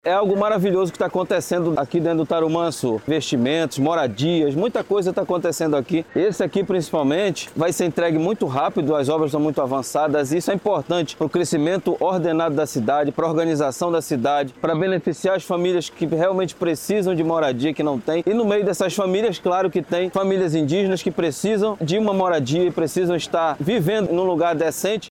SONORA-2-OBRA-RESIDENCIAL-.mp3